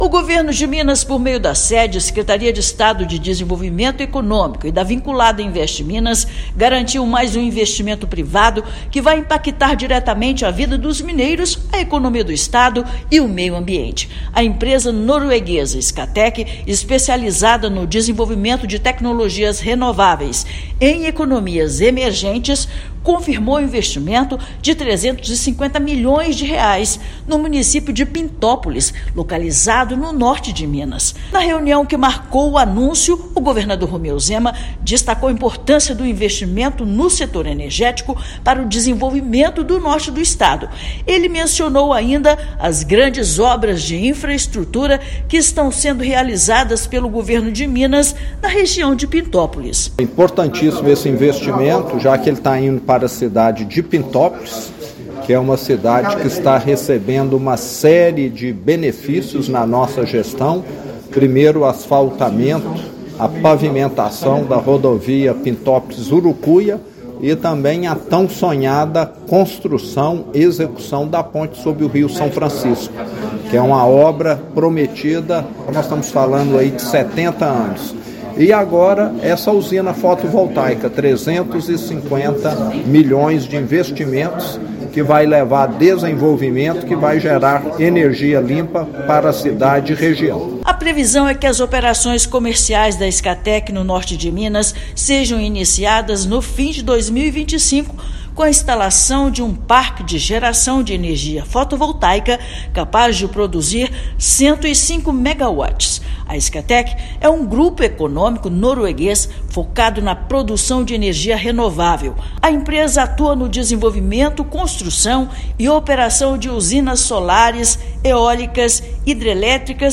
Scatec, empresa especializada na produção de energia renovável, implantará unidade no município de Pintópolis. Tratativas para atração do empreendimento foram conduzidas pelo Estado. Ouça matéria de rádio.